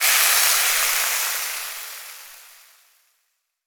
cooking_sizzle_burn_fry_07.wav